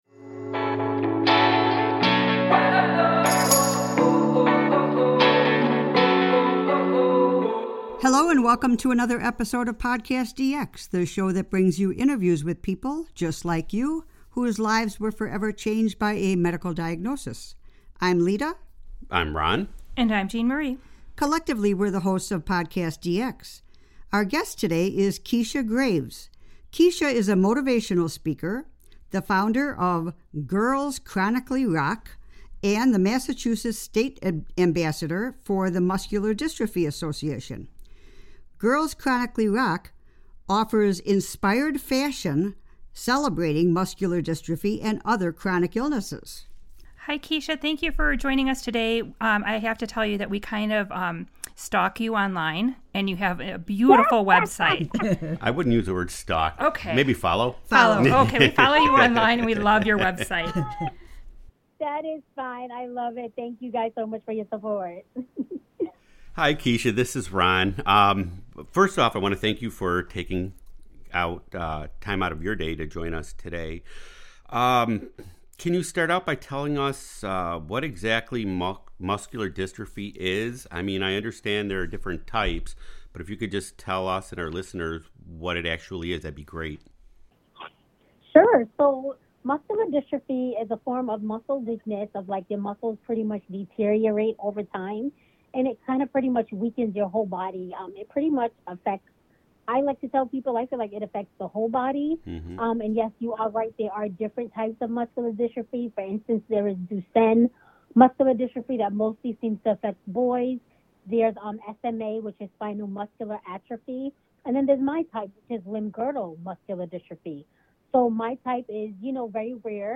This week we are speaking with a Muscular Dystrophy Warrior!